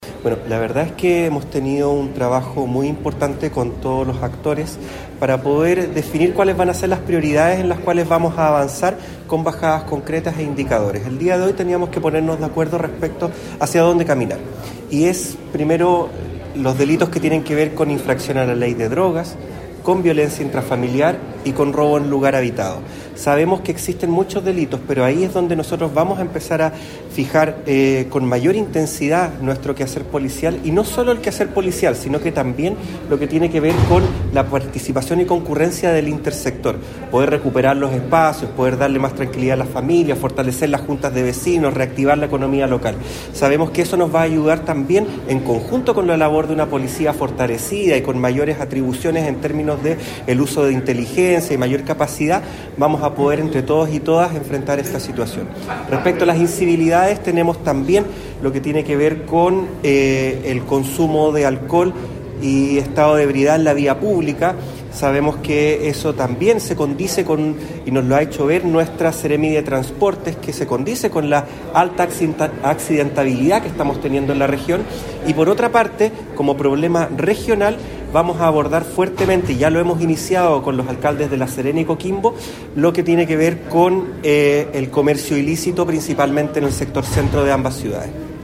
Delegado-Presidencial-Ruben-Quezada-1.mp3